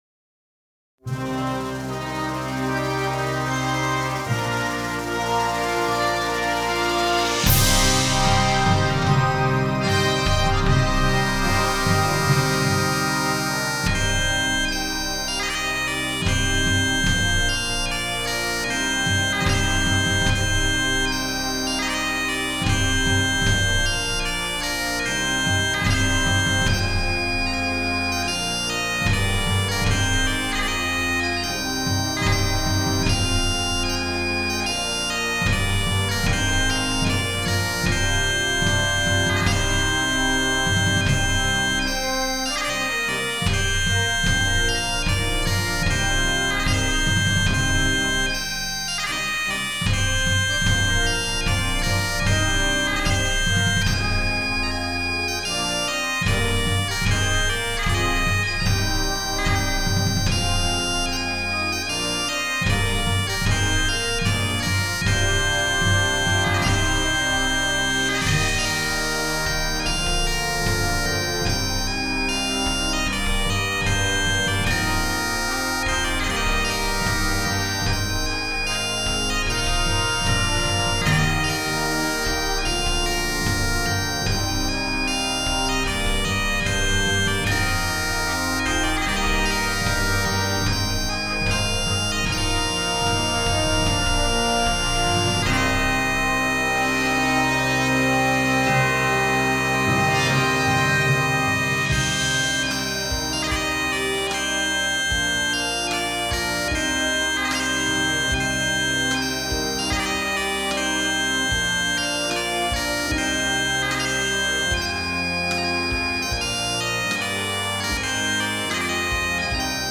CategoryConcert Band & Bagpipes
Timpani
Tubular Bells